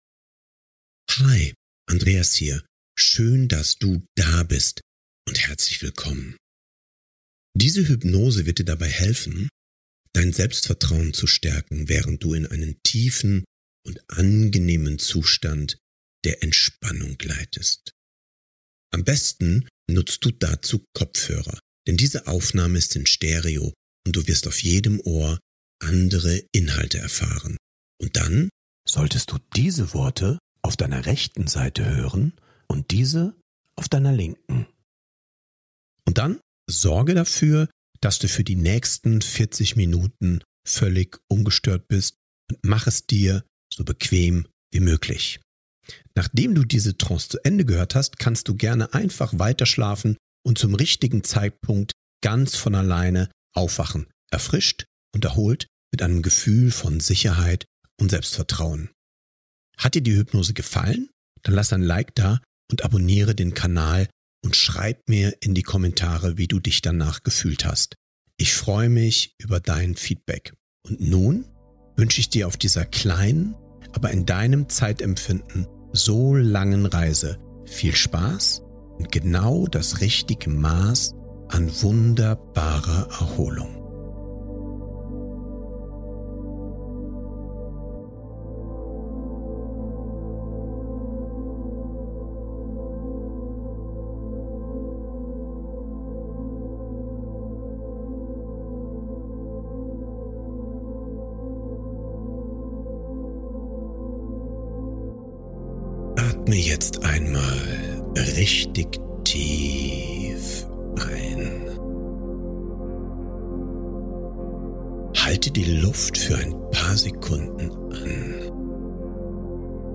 Beschreibung vor 1 Jahr Herzlich willkommen zu dieser Hypnose für mehr Selbstvertrauen – damit du innerlich stärker wirst und dich sicher und gelassen durch deinen Alltag bewegst.